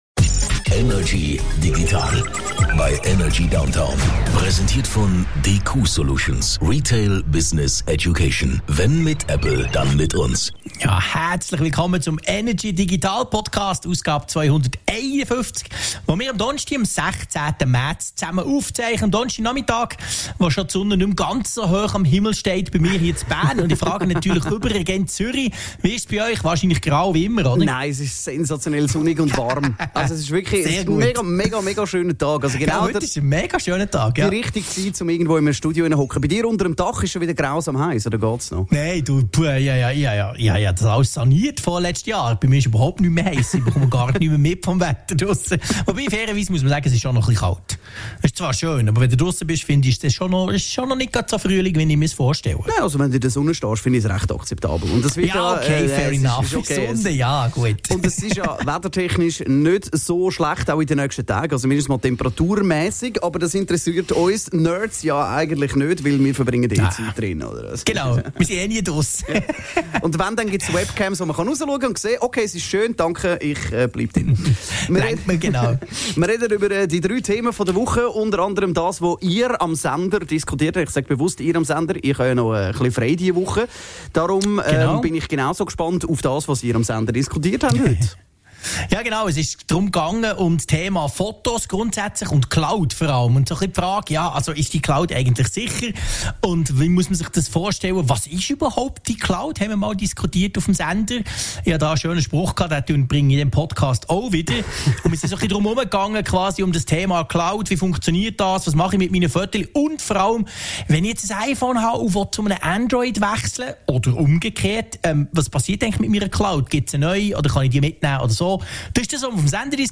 im Energy Studio
aus dem HomeOffice über die digitalen Themen der Woche.